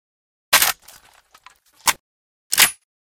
toz34_reload_one.ogg